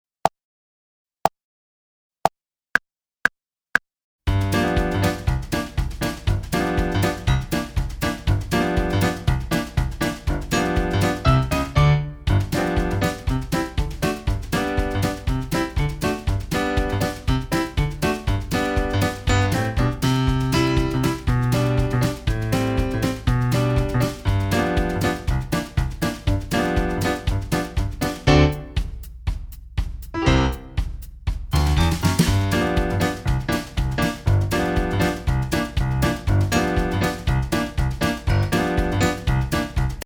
Upbeat songs that teach, not preach!